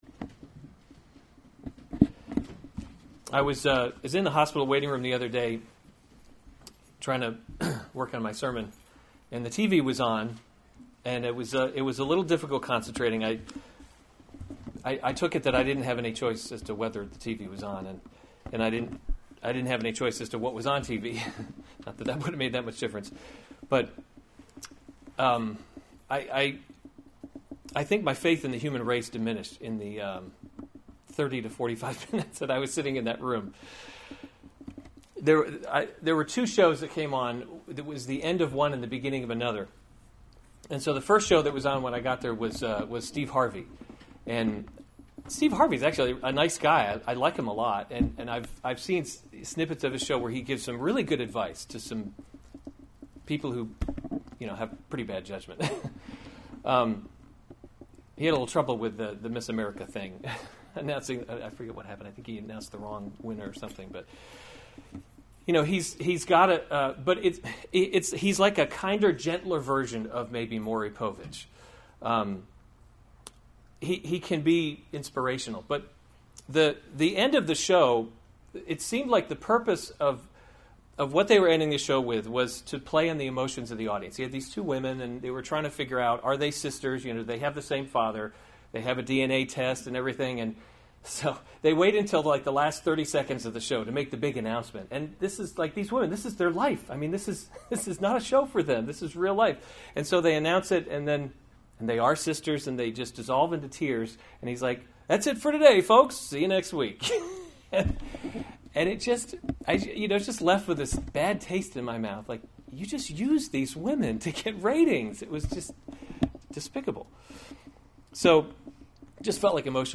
August 6, 2016 Psalms – Summer Series series Weekly Sunday Service Save/Download this sermon Psalm 39 Other sermons from Psalm What Is the Measure of My Days?